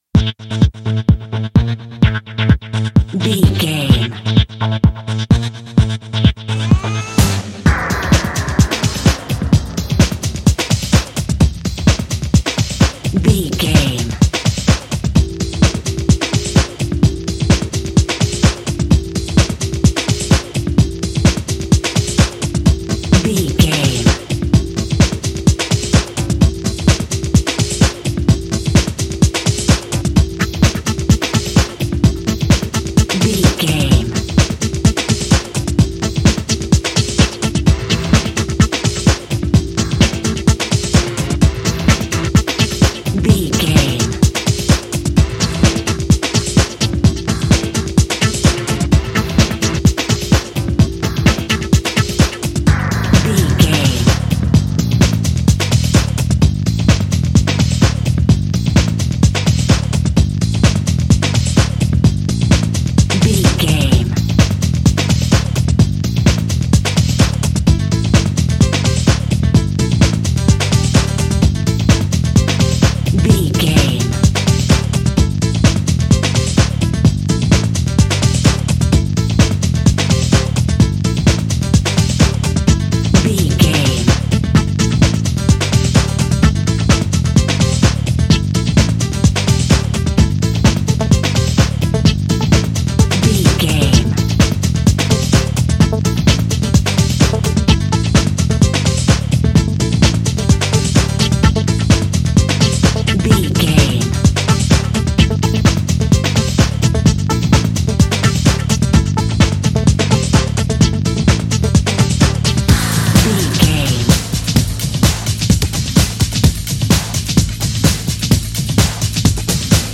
Aeolian/Minor
Fast
drum machine
synthesiser
electric piano
90s
Eurodance